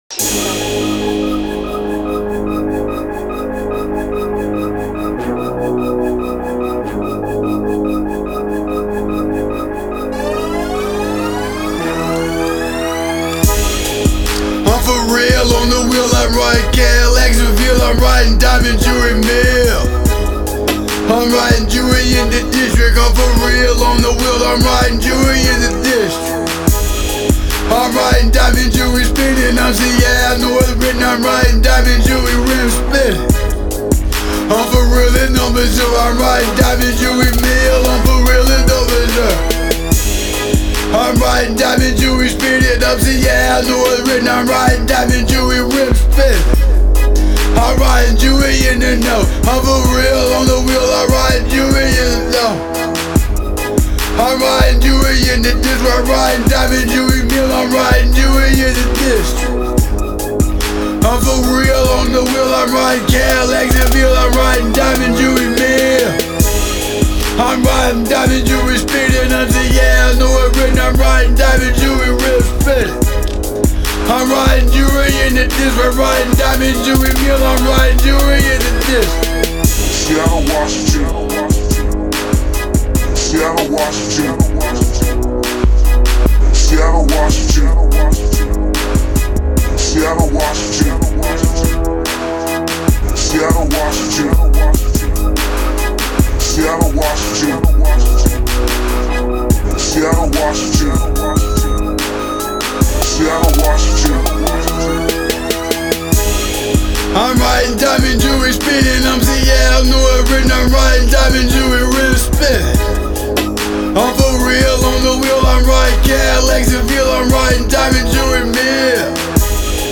The Oddities File – A SEATTLE HIP-HOP BLOG